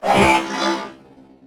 CosmicRageSounds / ogg / general / combat / enemy / droid / hurt3.ogg
hurt3.ogg